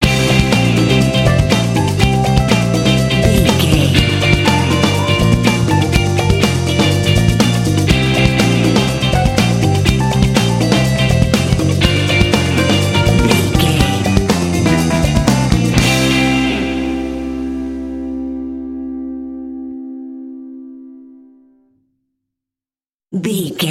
Uplifting
Aeolian/Minor
funky
groovy
bright
lively
energetic
bass guitar
electric guitar
electric organ
drums
percussion
Funk
jazz